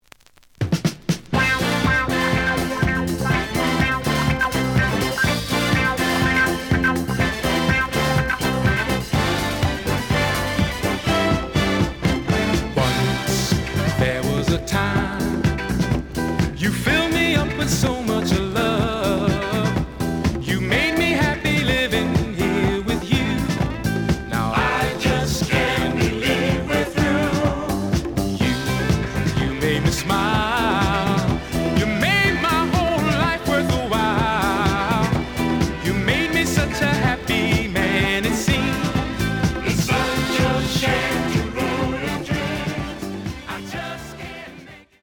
The audio sample is recorded from the actual item.
●Genre: Disco
A side plays good.